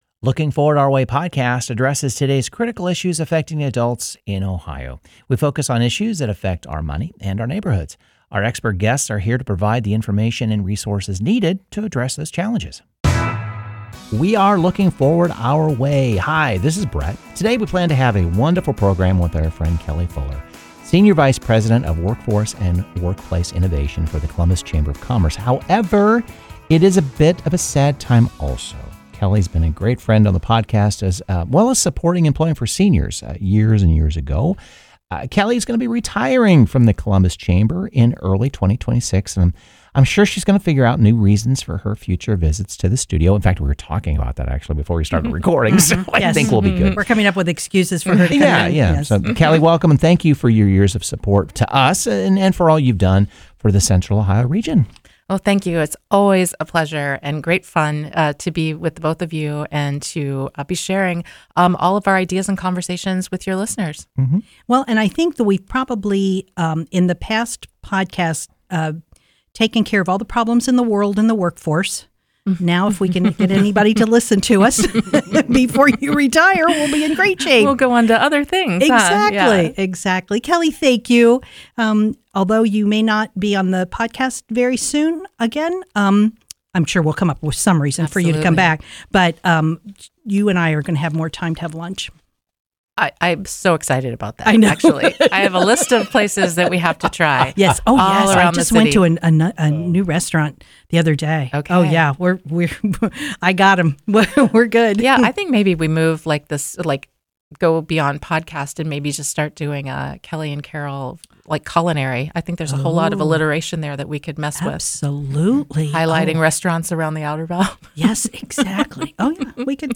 Plus, we don't shy away from discussing tough topics like affordable housing, evolving work environments, and what the future holds for both employers and job seekers. Whether you’re interested in the history of Central Ohio’s workforce, current labor market trends, or you just want some thoughtful career advice, this episode offers valuable wisdom—and a few laughs along the way.